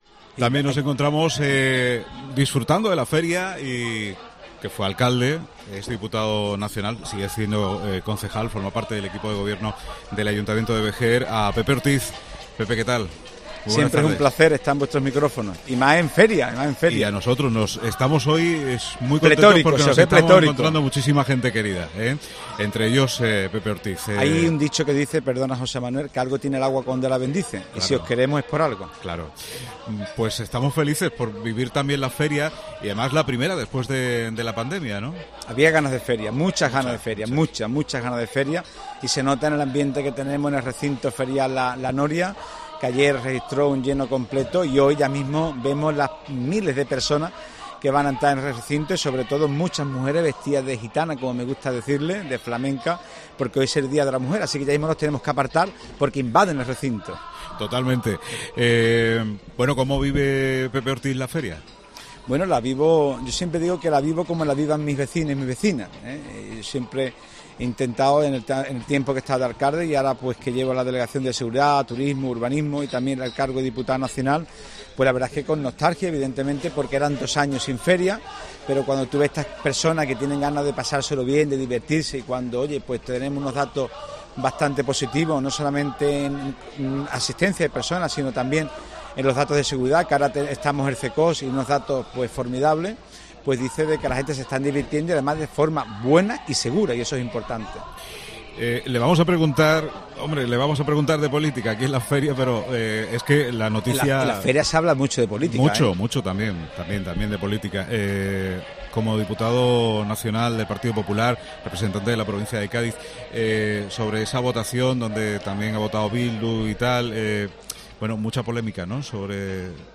entrevista al Diputado Nacional del PP por Cádiz Pepe Ortiz